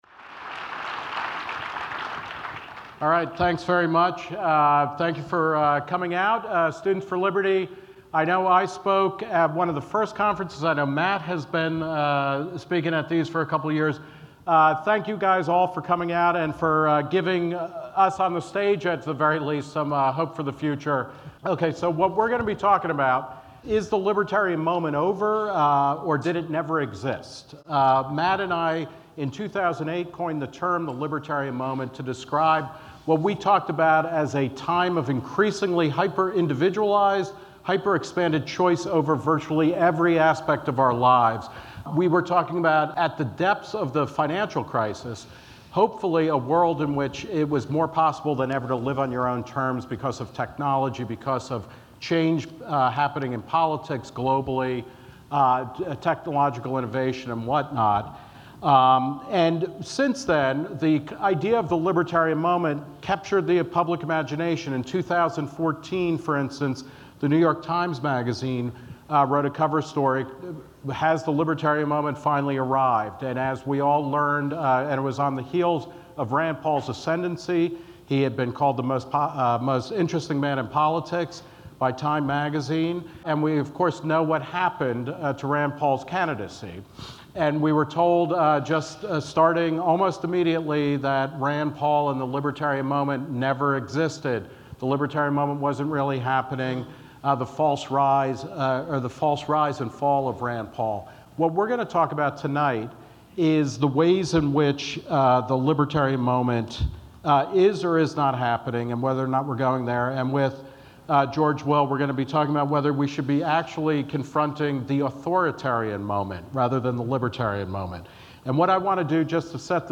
for an opening-night interview at the International Students For Liberty Conference